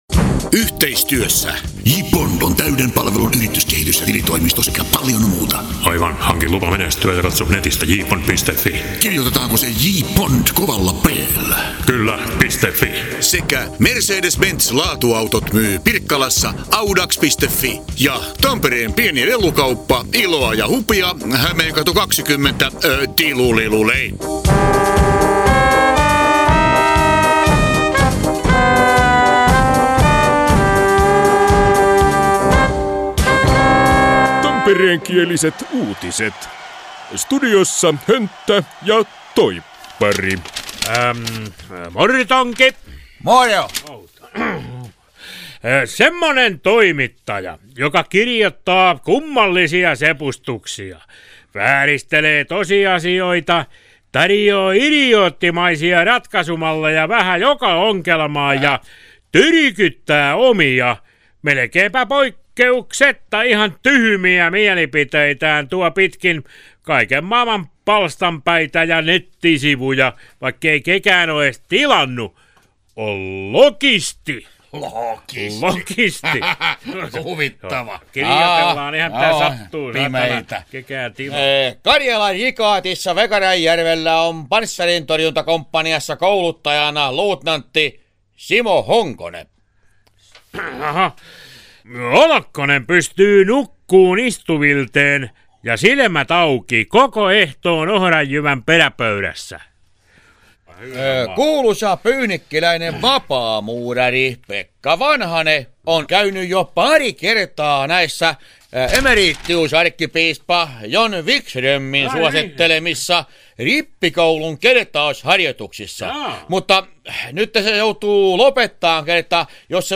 Tampereenkiäliset uutiset